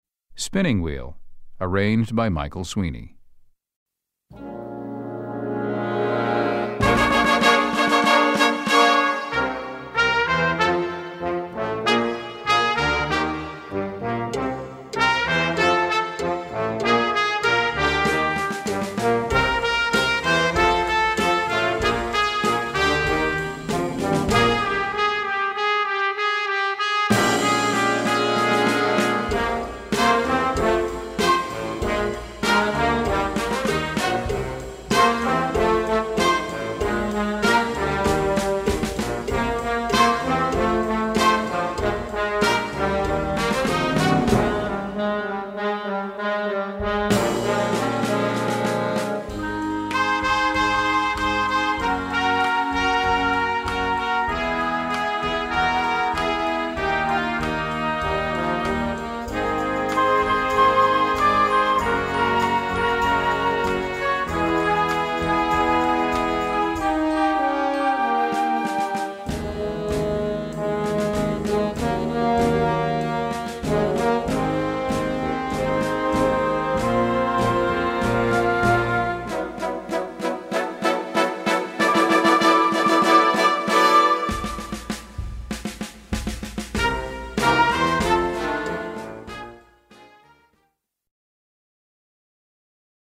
Gattung: Blasmusik für Jugendkapelle
Besetzung: Blasorchester
Rocknummer